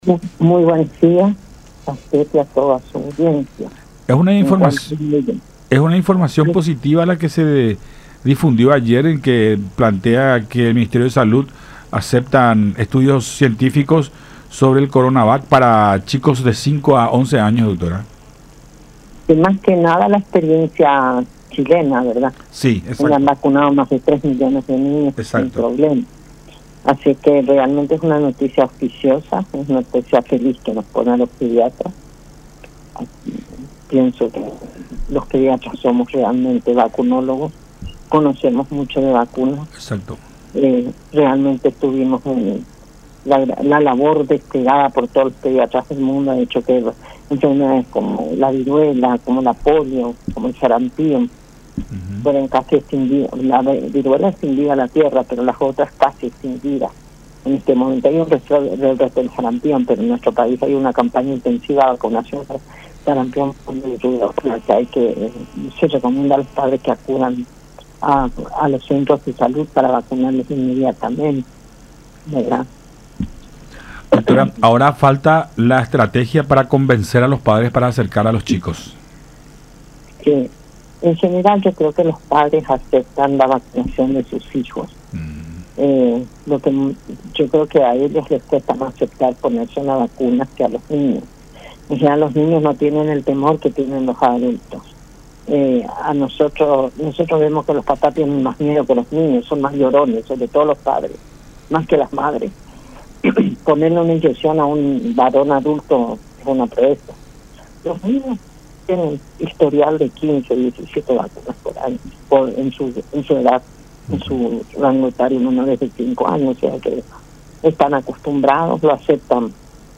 en diálogo con Enfoque 800 a través de La Unión